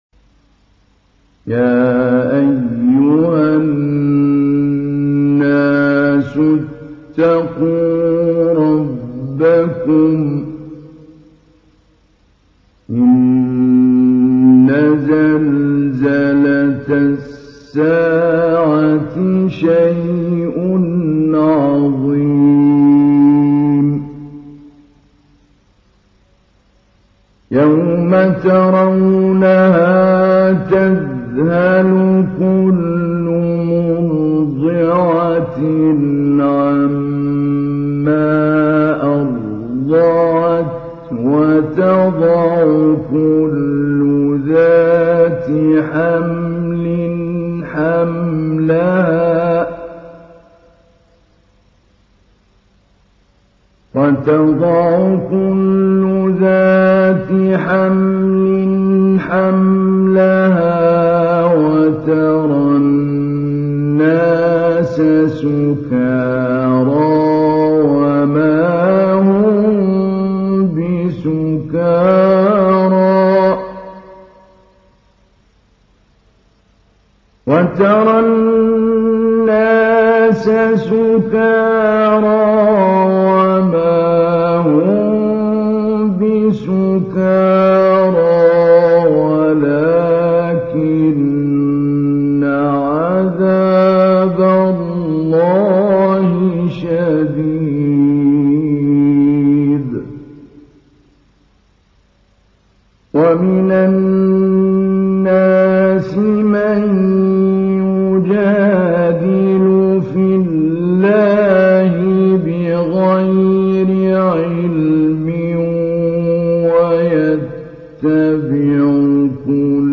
تحميل : 22. سورة الحج / القارئ محمود علي البنا / القرآن الكريم / موقع يا حسين